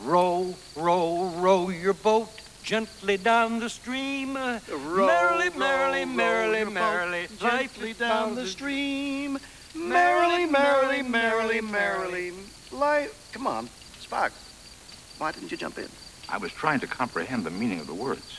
singing